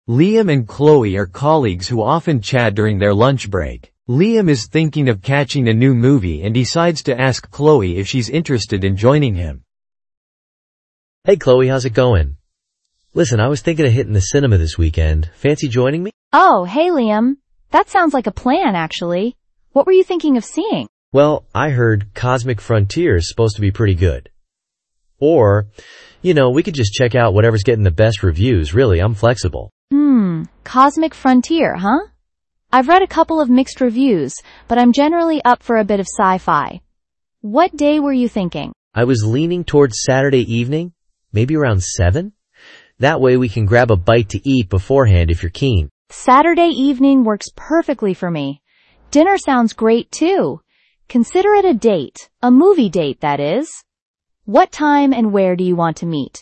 Làm sao để tạo ra nội dung file với giọng đọc của native speaker tự nhiên nhất
Rồi mình lại nghĩ: nếu trong một câu chuyện, có nhiều người tham gia, thì việc đọc có khi lại không hay bằng việc tạo ra nhiều giọng thoại.
Rồi cuối cùng cũng ra được một giải pháp tương đối ưng ý mà không cần dùng đến feature multi speakers của Google.
demo-2-person-dialogs.mp3